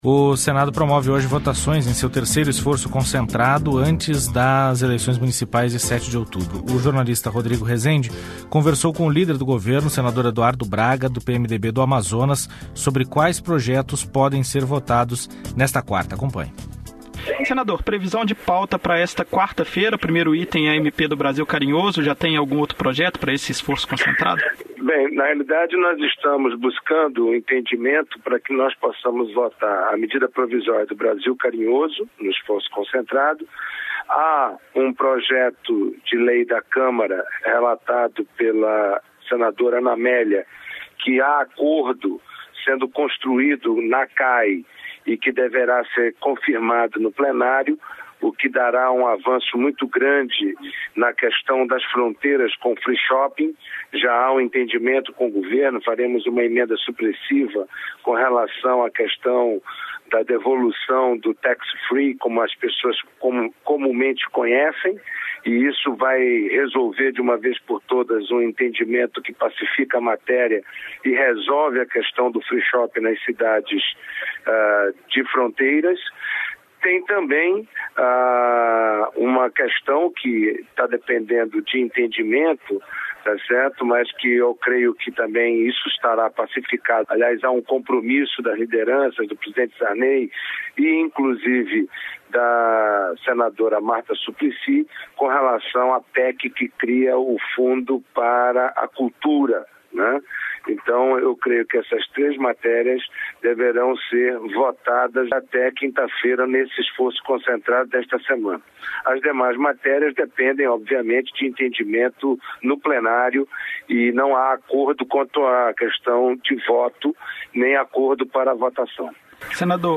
Entrevista com o senador Eduardo Braga (PMDB-AM), líder do governo.